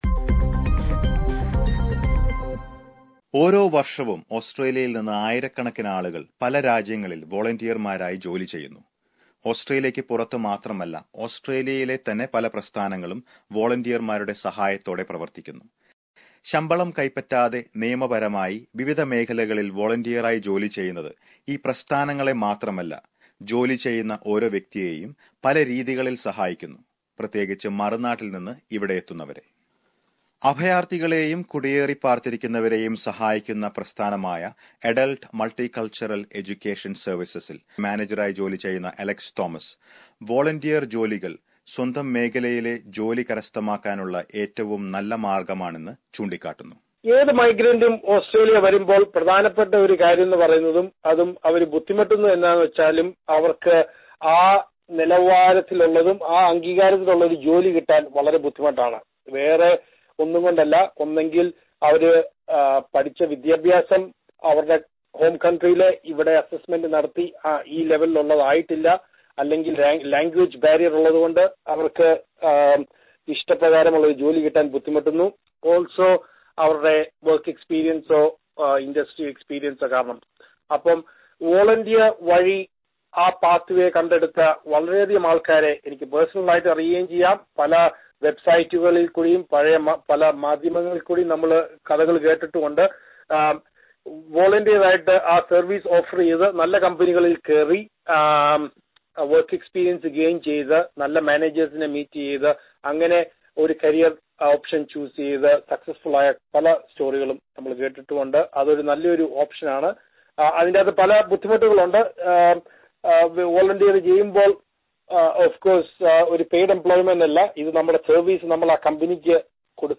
Listen to a report on the various aspects of volunteering in Australia